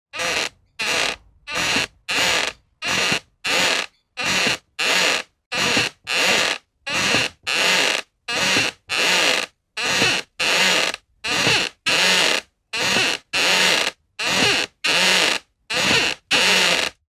zapsplat_household_rocking_chair_creak_16356